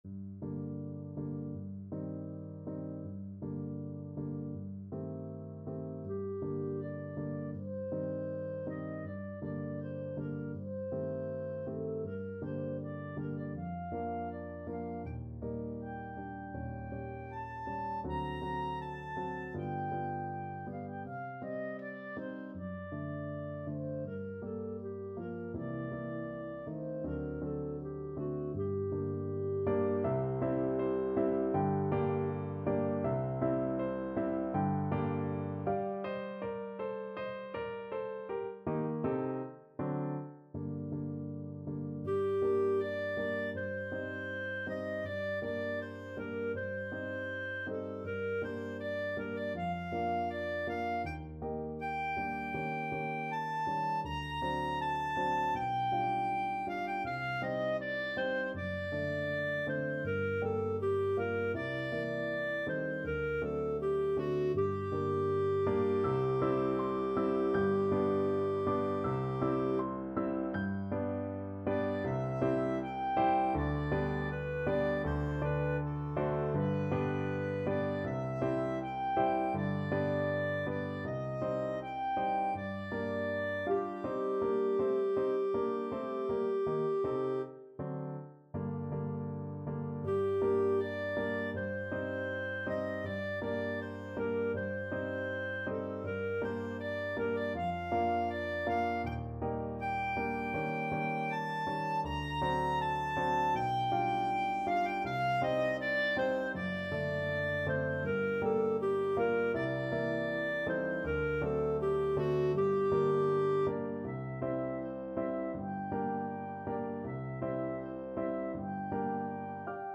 ~ = 100 Andante
2/4 (View more 2/4 Music)
Classical (View more Classical Clarinet Music)